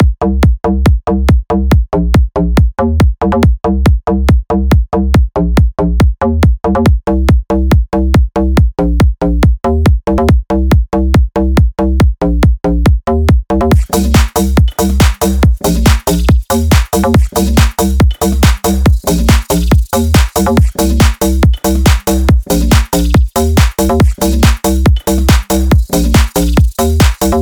Dexed прикольно донкает) В отличие от FM8 более чистый и упругий. Тут по четыре такта на каждый тембр (сначала идёт FM8). Ко всем добавлен сэмпл Timbale L с TR-727.